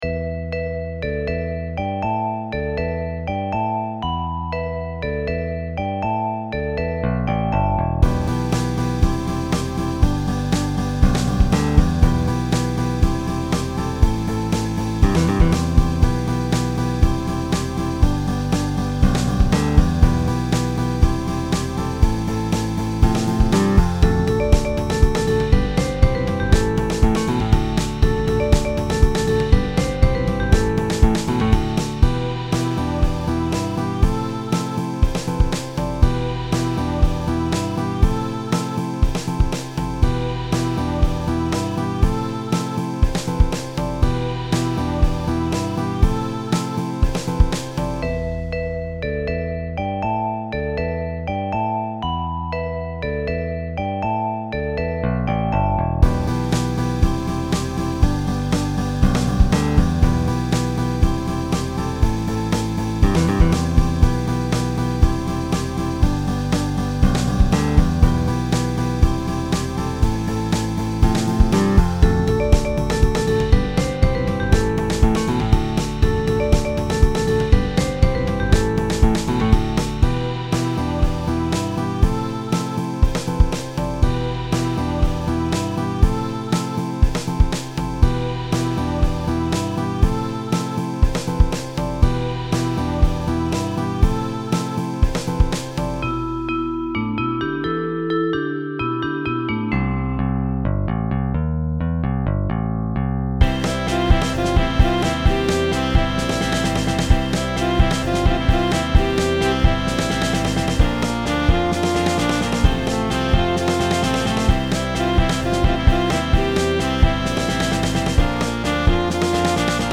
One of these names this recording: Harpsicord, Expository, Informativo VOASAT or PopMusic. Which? PopMusic